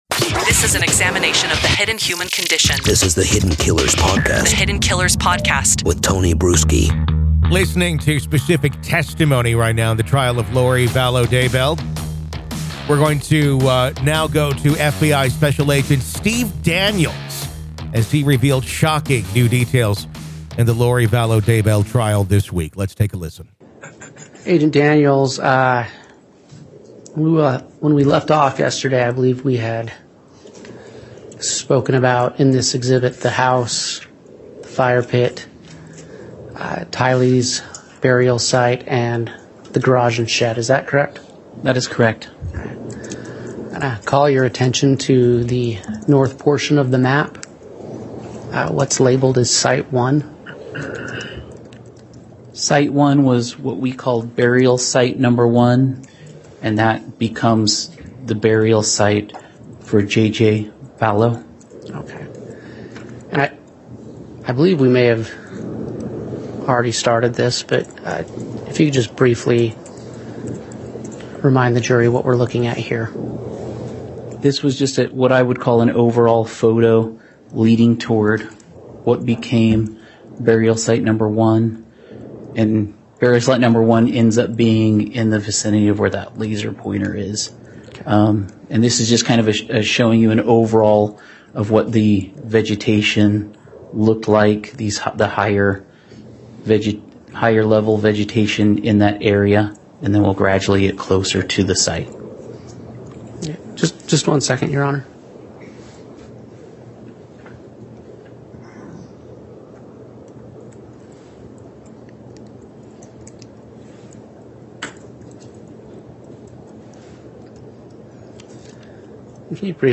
The Trial Of Lori Vallow Daybell | Full Courtroom Coverage